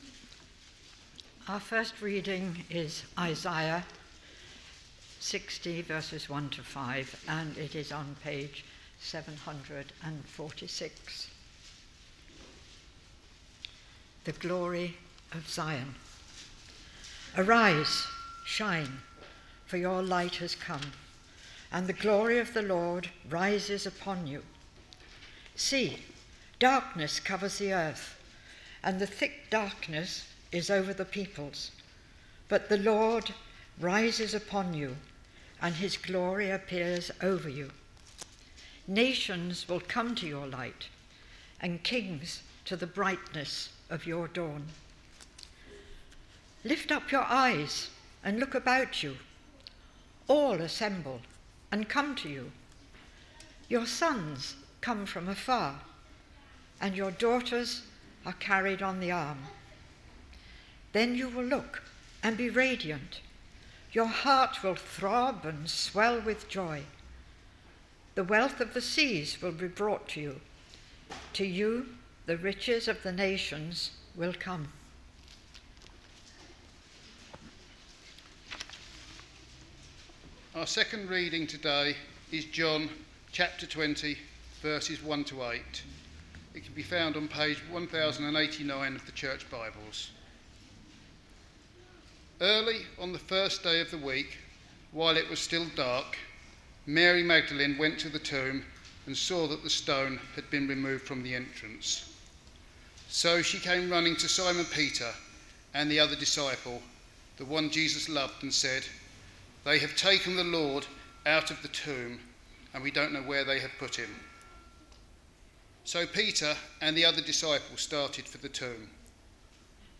Readings-Sermon-on-5th-April-2026.mp3